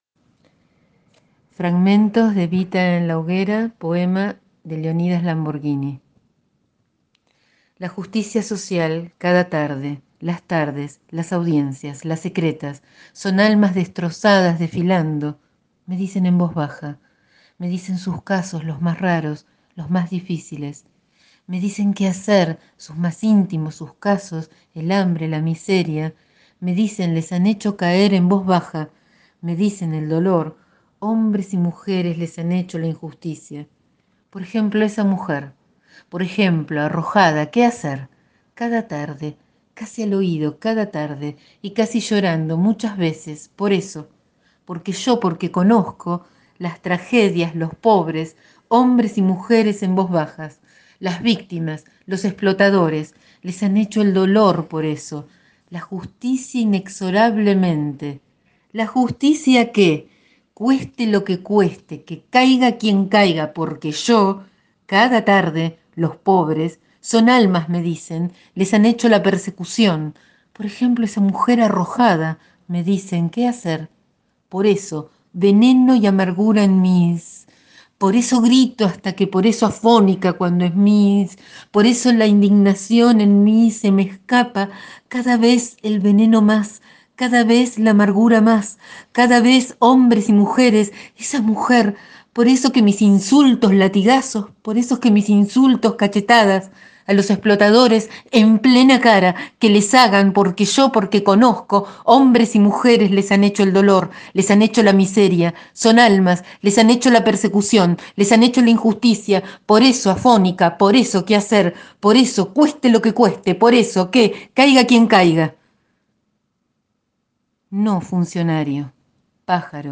Hoy, recordando el nacimiento de Eva Duarte de Perón un 7 de mayo de 1919 en Los Toldos-Buenos Aires, elijo leer fragmentos del poema célebre, “Eva Perón en la hoguera“ de Leónidas Lamborghini publicado en 1972.